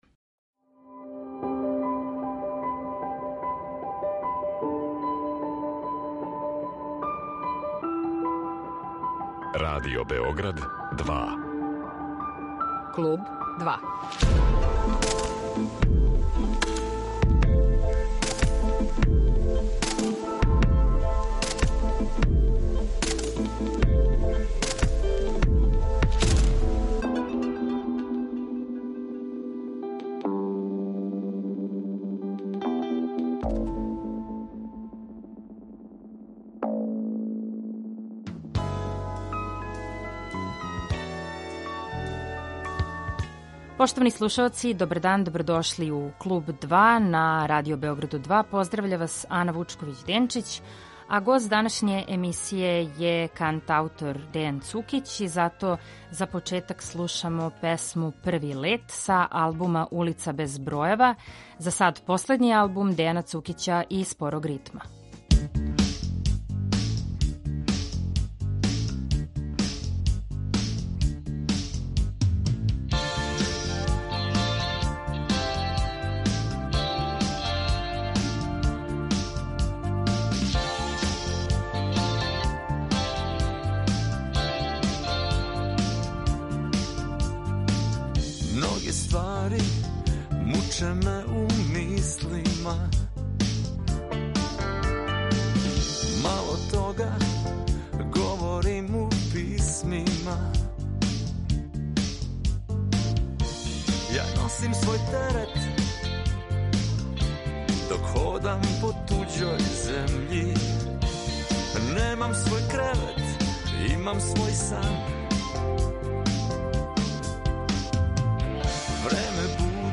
Гост емисије је Дејан Цукић